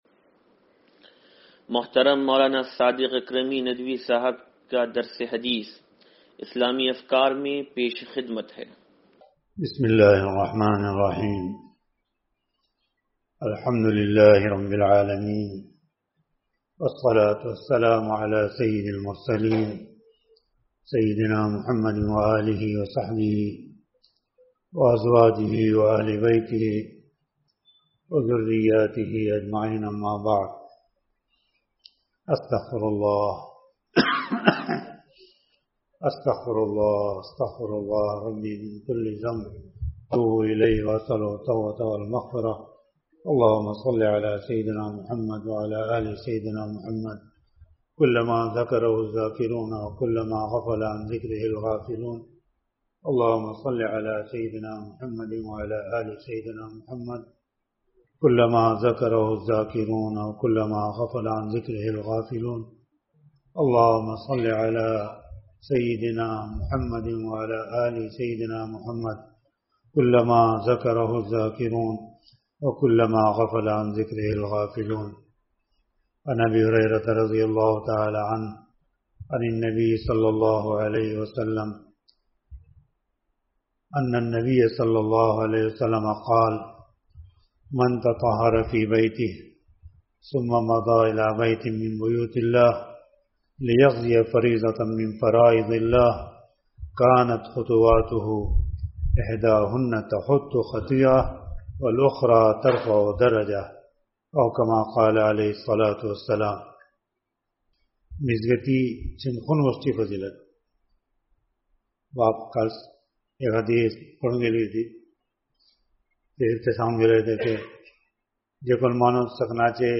درس حدیث نمبر 0811